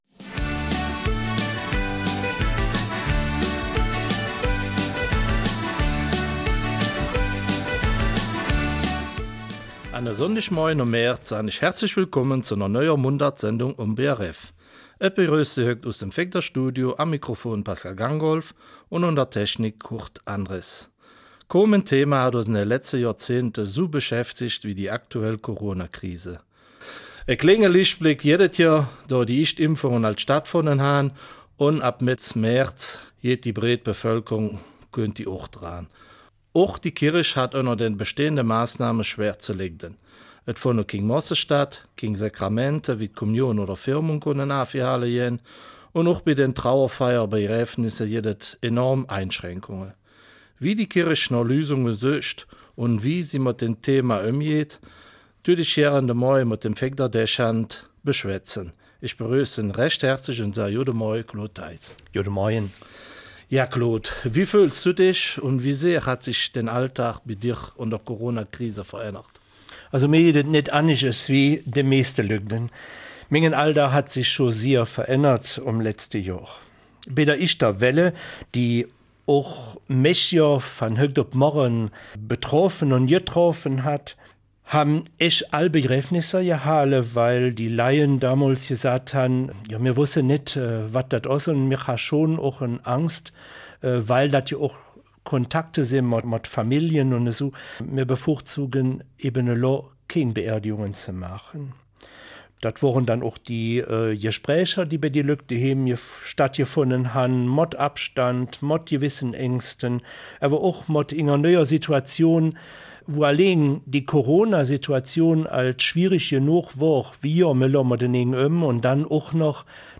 Eifeler Mundart: Kirche in Corona-Zeiten